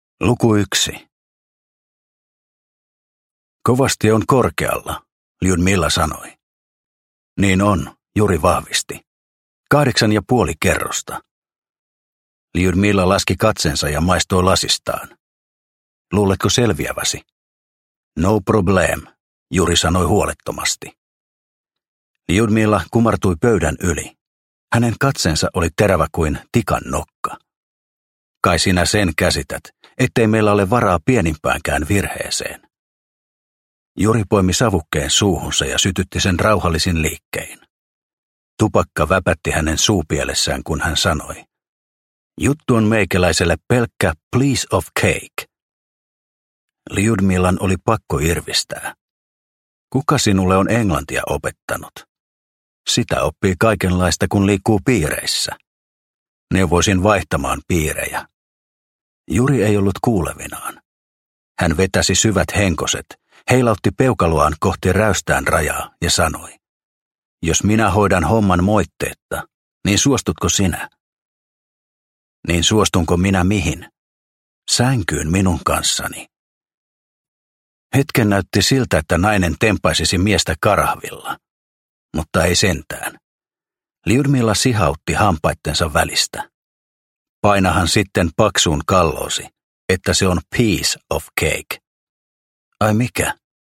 Tiikeripyton – Ljudbok – Laddas ner